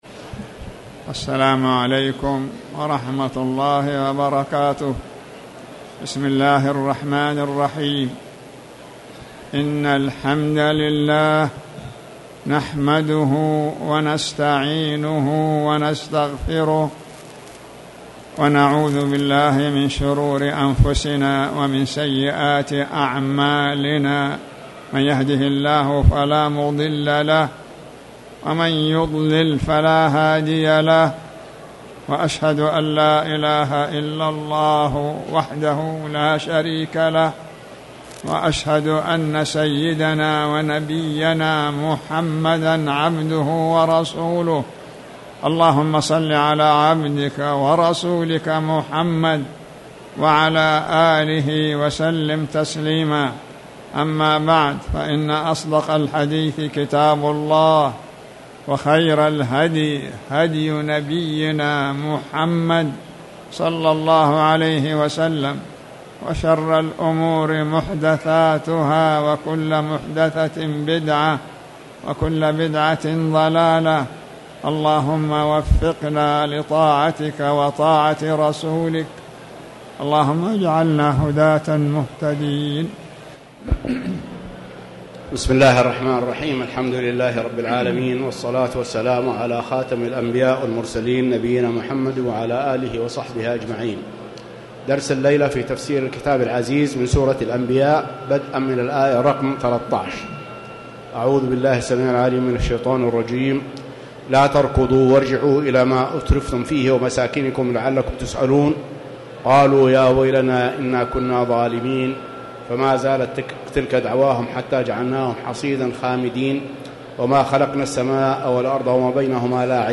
تاريخ النشر ٢٣ شوال ١٤٣٨ هـ المكان: المسجد الحرام الشيخ